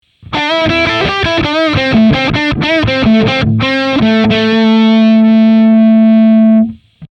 Riff 1 (A) Modern High Gain
• A: Logic Pro's Amp Designer, "Modern British Stack" (Circuit Emulation)
riff1_A.mp3